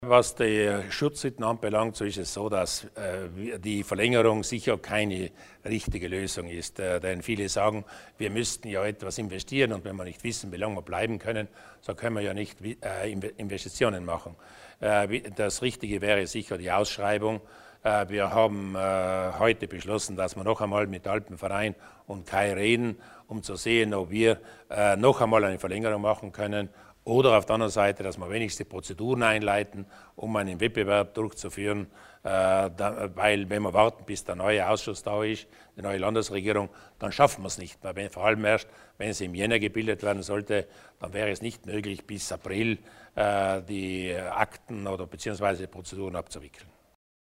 Landeshauptmann Durnwalder zur zukünftigen Führung der Schutzhütten